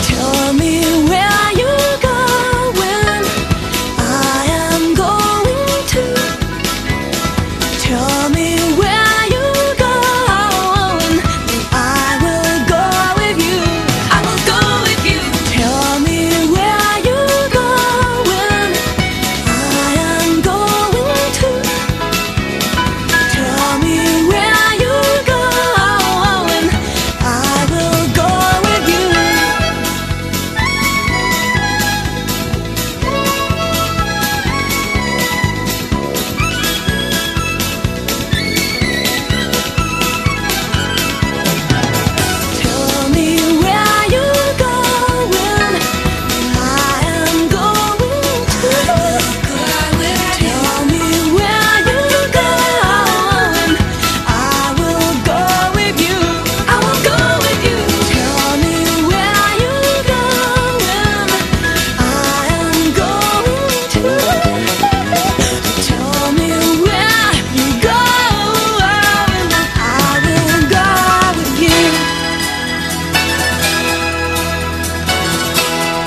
GARAGE PUNK / PSYCHEDELIC ROCK
サウンドは60'Sリスペクトながら、全曲に90年代の粗野で生々しい息吹が吹き込まれています。